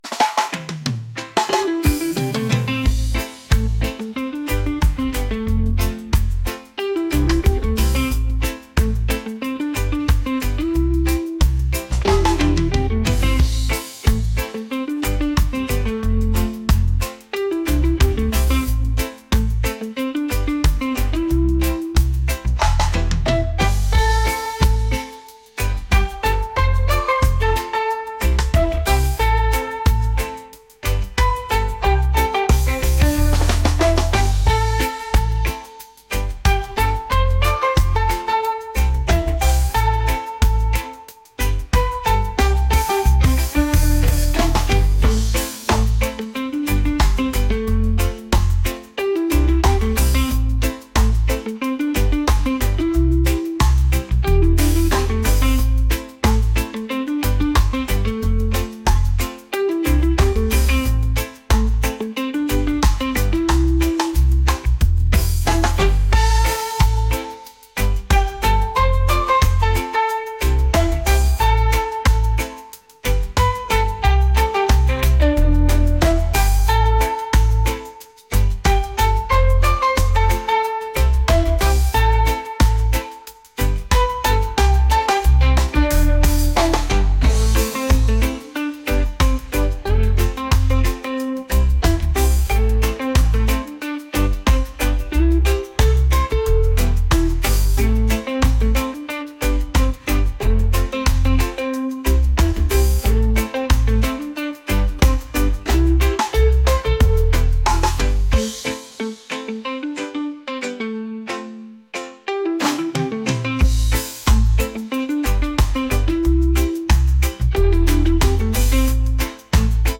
reggae | funk